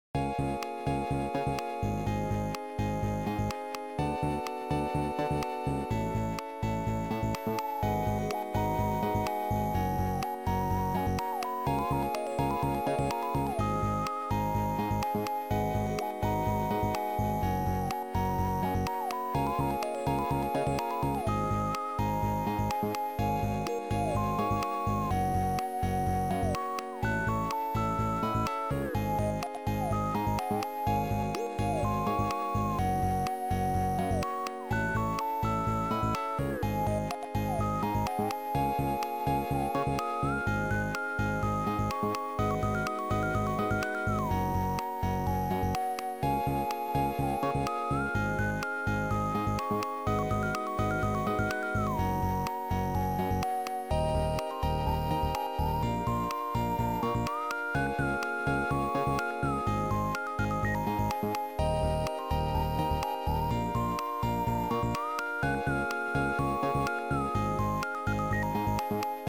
Sound Format: Noisetracker/Protracker
Chip Music Pack